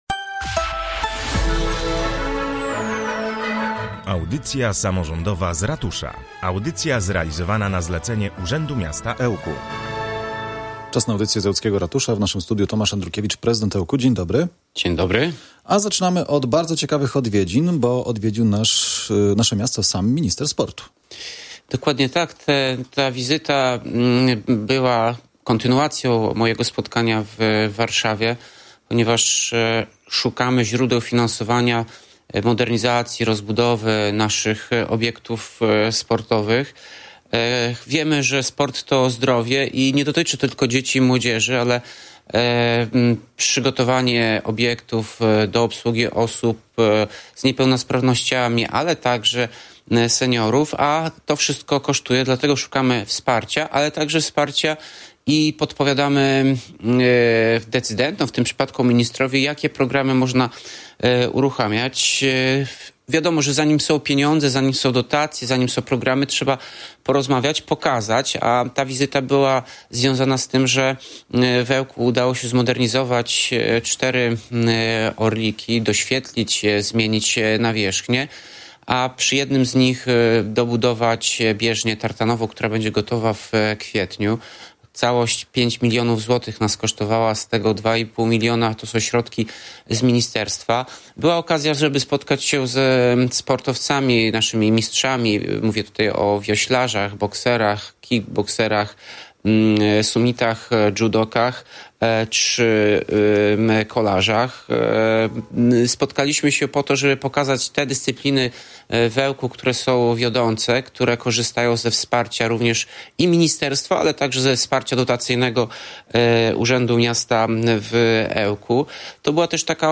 Tomasz Andrukiewicz, prezydent Ełku
20-01-Tomasz-Andrukiewicz-audycja-z-jinglami.mp3